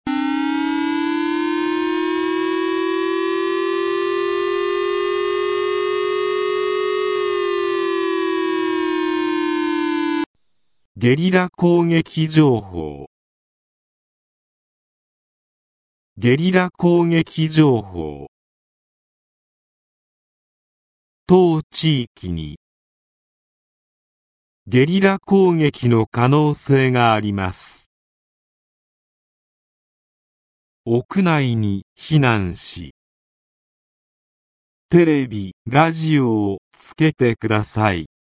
全国瞬時警報システム（J－ALERT）による放送例
武力攻撃等に関する情報の通報（例）